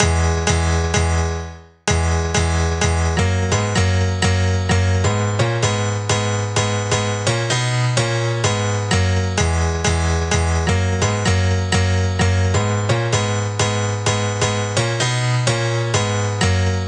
VTDS2 Song Kit 09 Pitched Sneaking On The DF Piano.wav